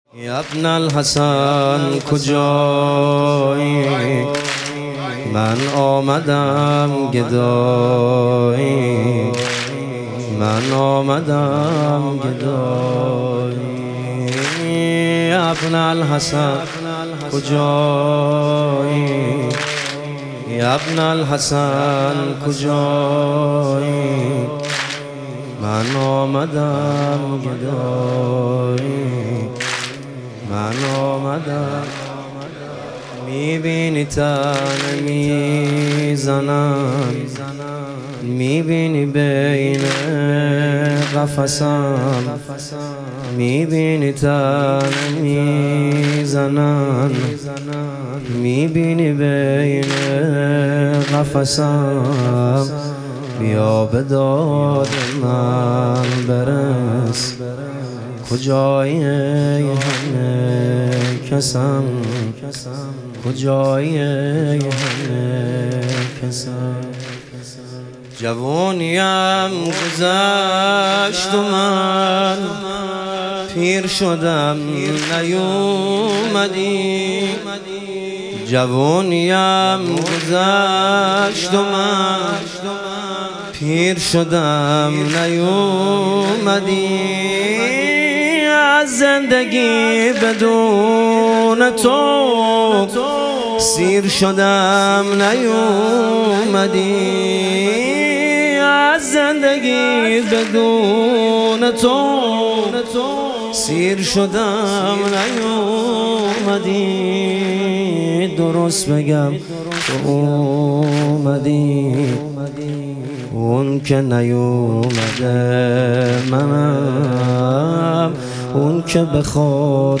شهادت امام هادی علیه السلام - واحد